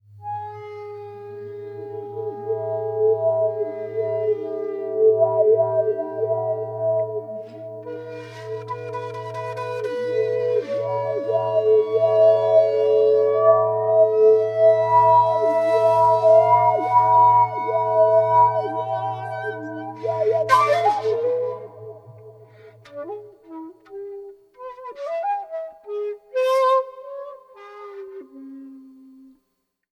Two complete works for shakuhachi und live electronics
('Electric breath music')